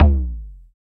LOGTOM LO1F.wav